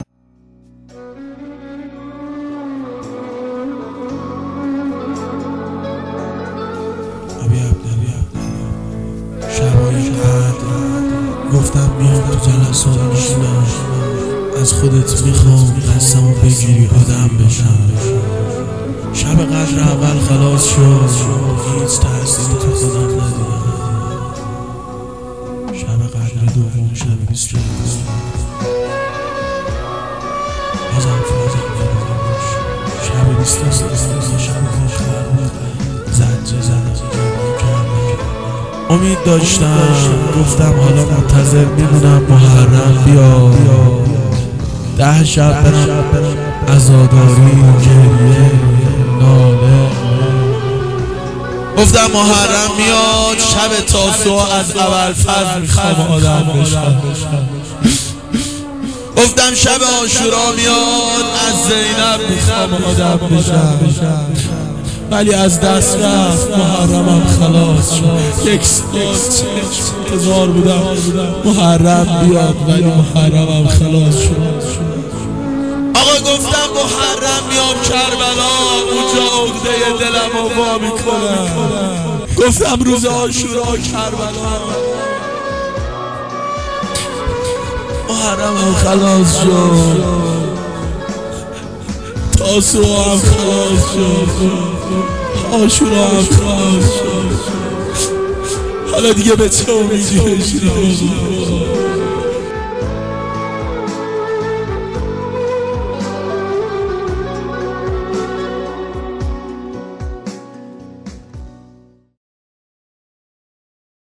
گلچین مداحی